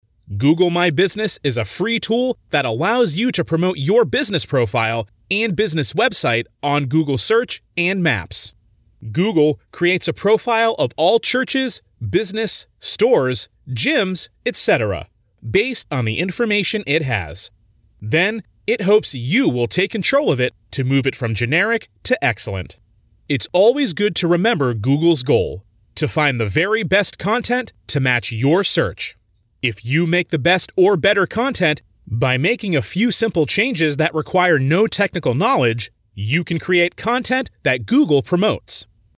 专题配音